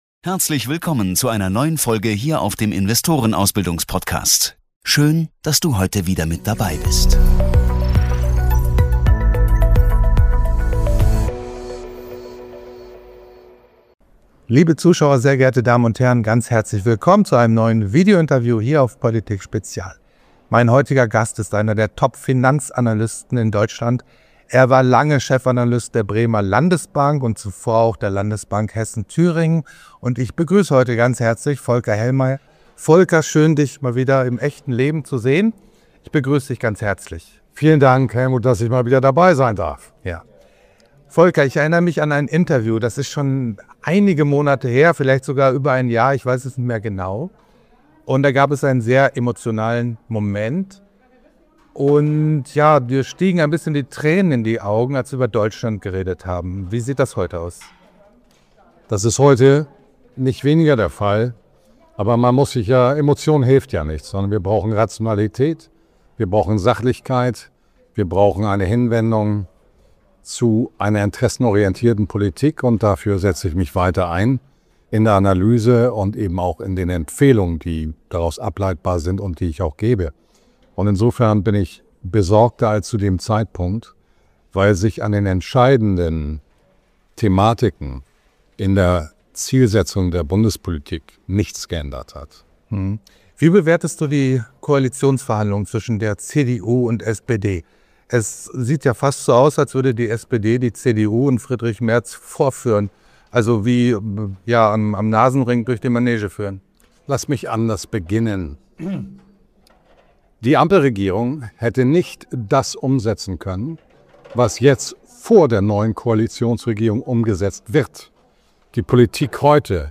In diesem spannenden Gespräch